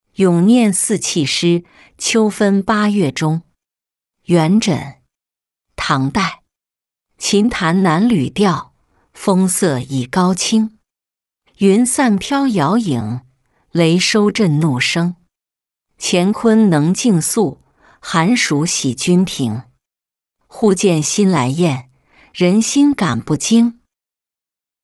咏廿四气诗·秋分八月中-音频朗读